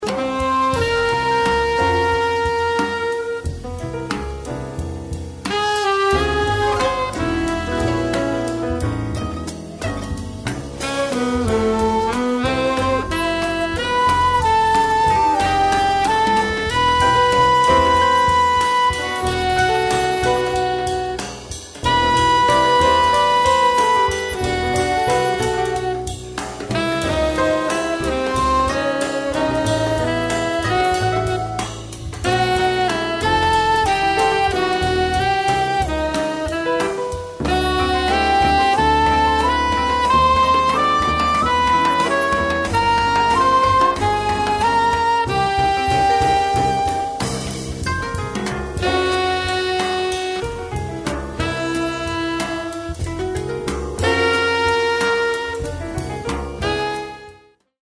A studio recording of this intercultural ensemble
shakuhachi
koto
saxophones
bass
drums